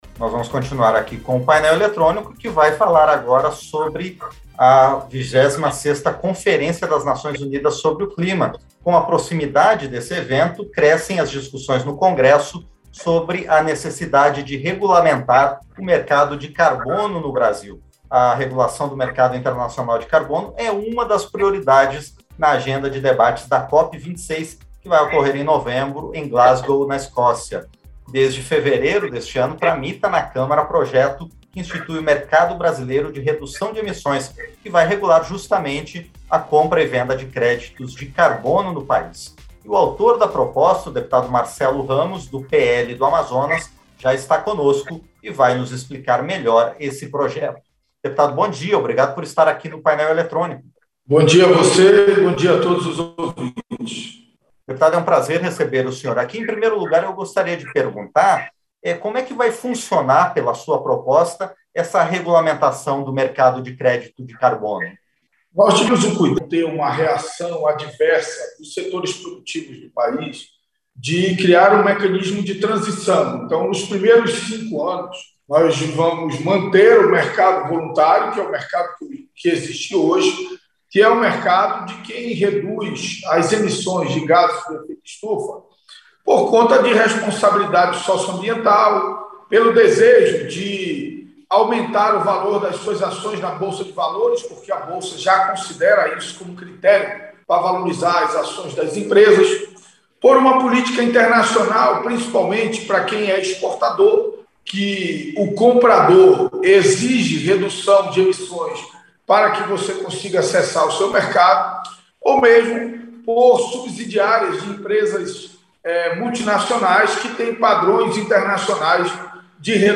• Entrevista - Dep. Marcelo Ramos (PL-AM)
Programa ao vivo com reportagens, entrevistas sobre temas relacionados à Câmara dos Deputados, e o que vai ser destaque durante a semana.